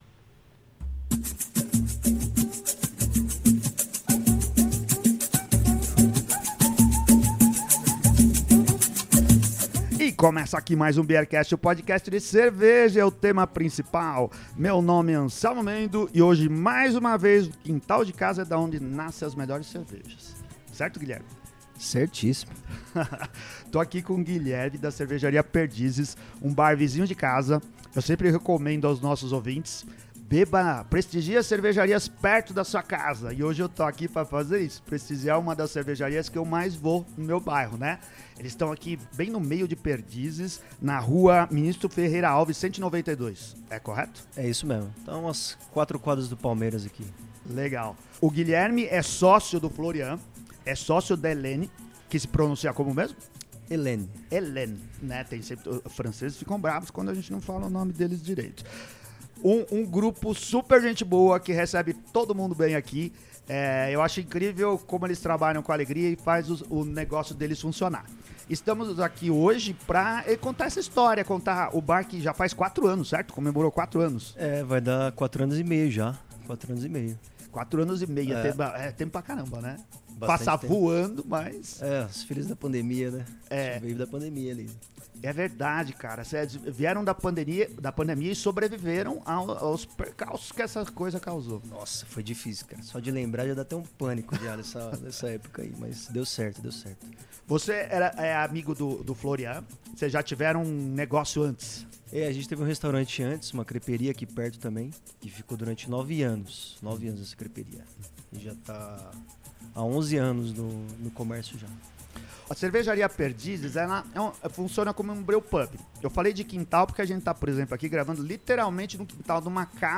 Entrevistei